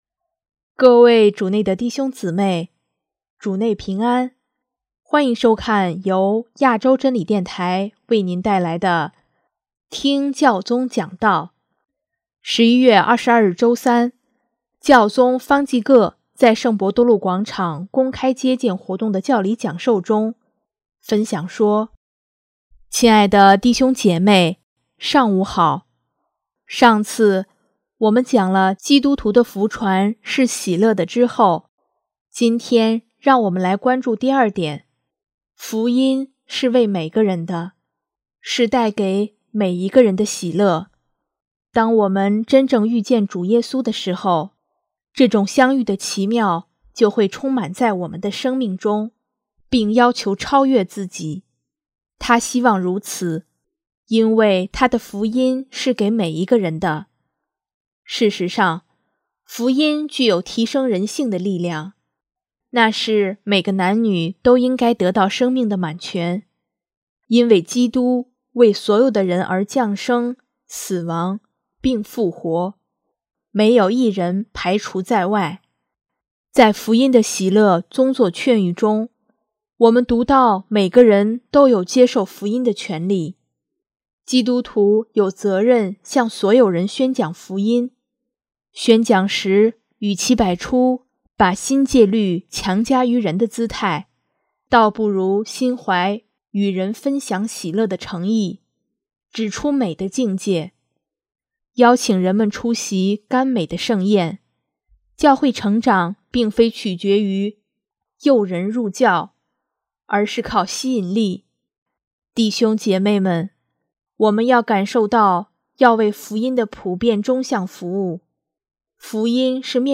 11月22日周三，教宗方济各在圣伯多禄广场公开接见活动的教理讲授中，分享说：